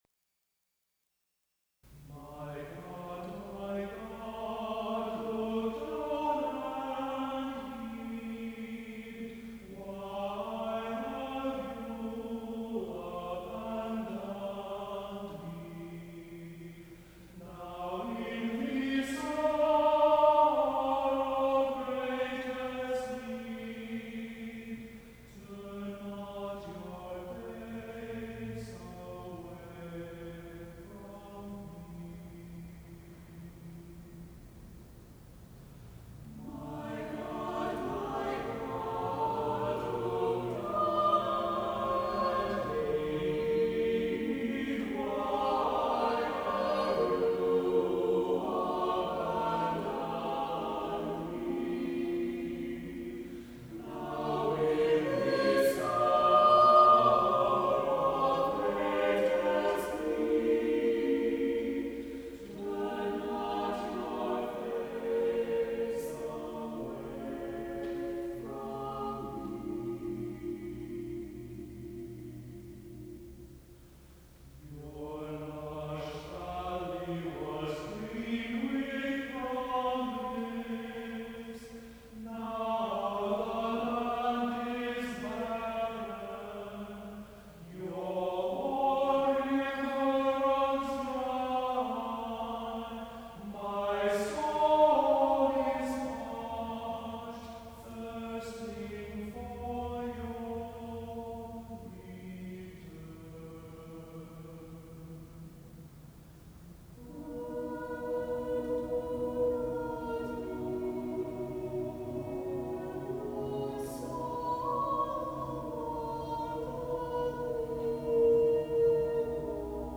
is heightened by this haunting choral setting.
SATB a cappella